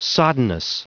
Prononciation du mot soddenness en anglais (fichier audio)
Prononciation du mot : soddenness